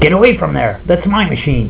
Most are attracted more to the entrancing sound of the nickel slots, what with their worse than midi music bass-tone song, the quarter
slots.wav